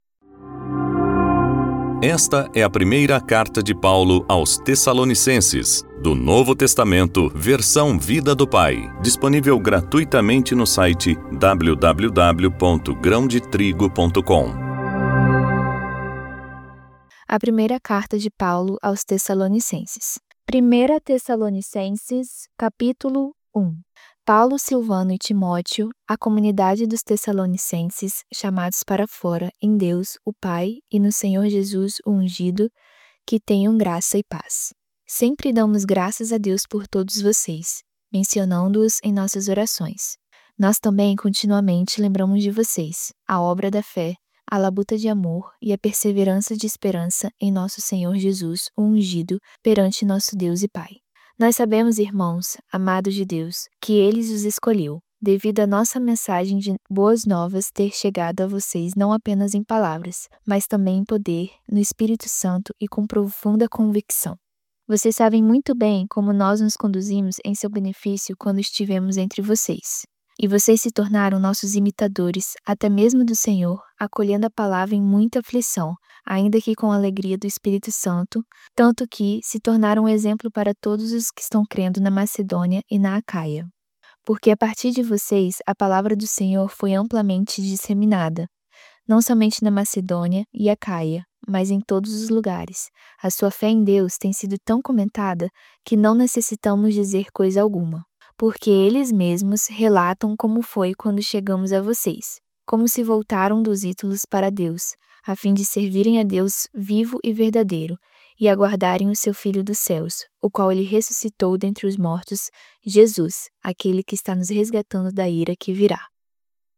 voz-audiobook-novo-testamento-vida-do-pai-primeira-tessalonicenses-capitulo-1.mp3